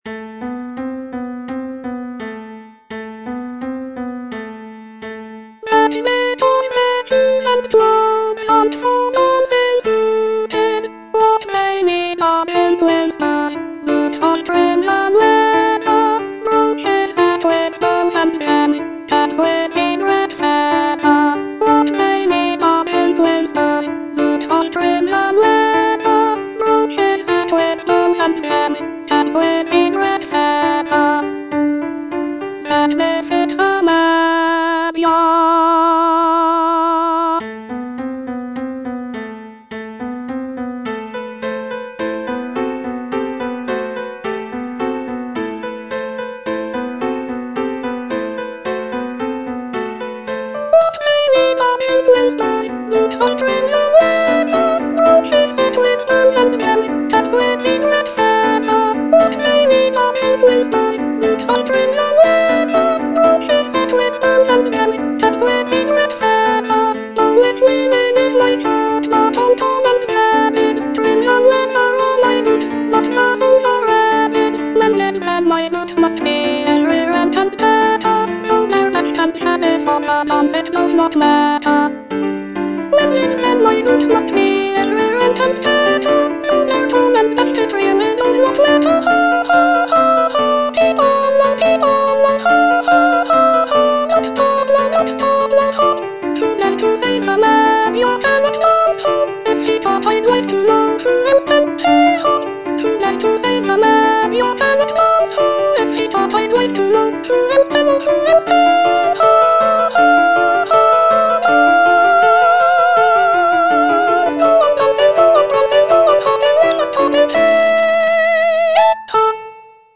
Soprano I (Voice with metronome)
is a lively and rhythmic piece